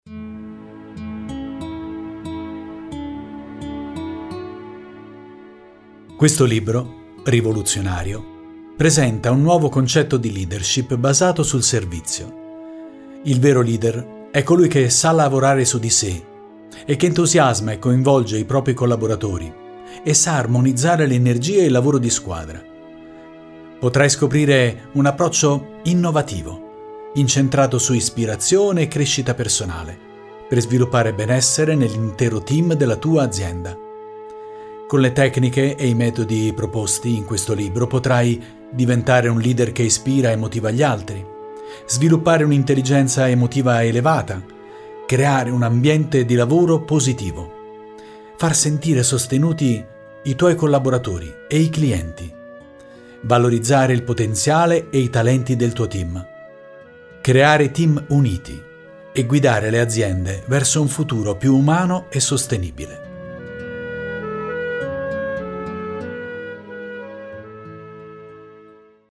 audiolibro
Registrato presso il Jyoti Studio di Ananda Assisi nel 2025.